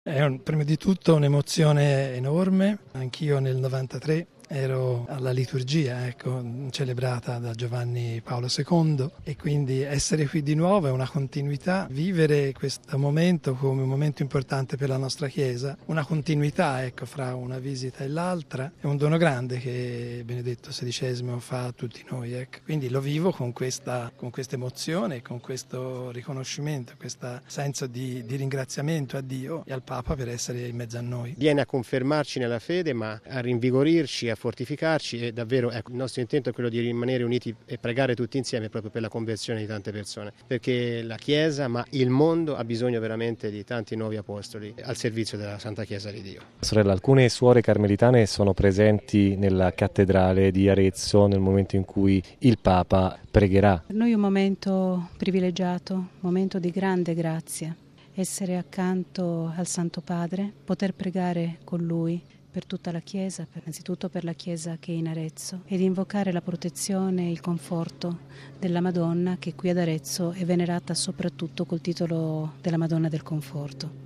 Alla veglia hanno partecipato anche alcuni dei diaconi che sono stati sul palco, questa mattina, con il Papa durante la Messa e le suore carmelitane che incontrano Benedetto XVI nella Basilica di San Donato, presso la cappella della Madonna del Conforto.